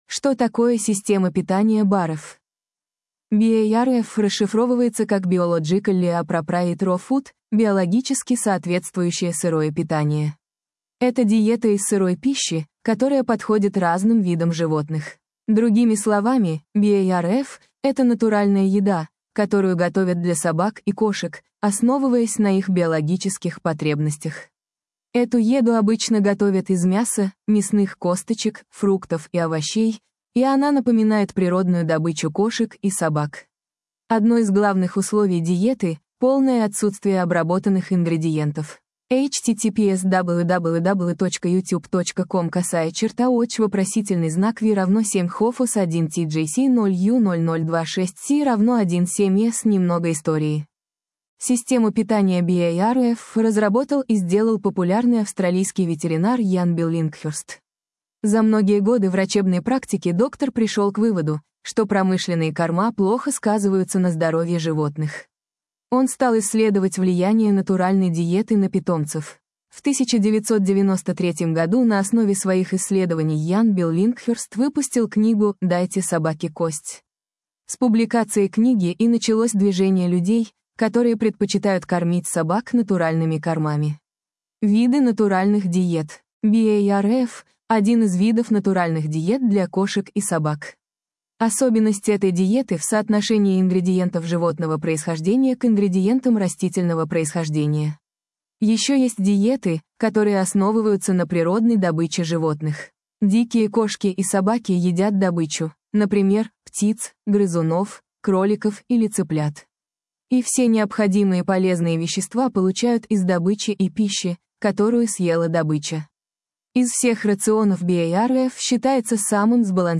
Главная - Питание - Что такое система питания BARF для кошек и собак — рассказывает ветеринар